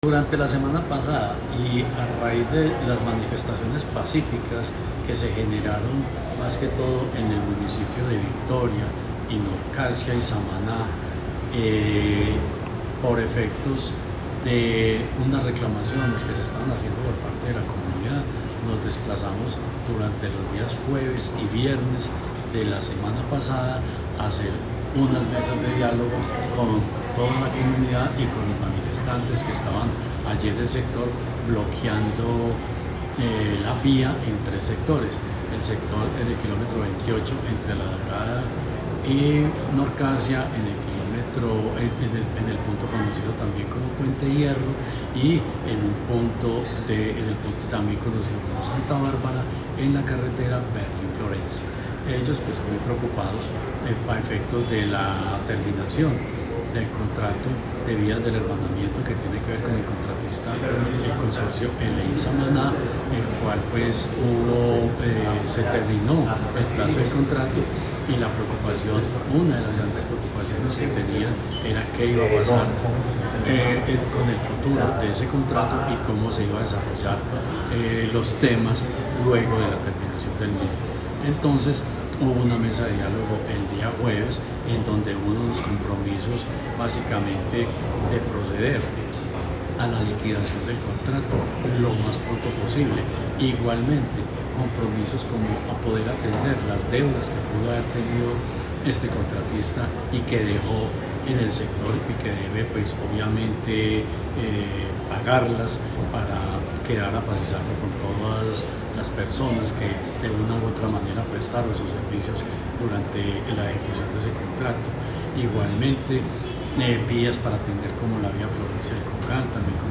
Secretario de Infraestructura de Caldas, Jorge Ricardo Gutiérrez.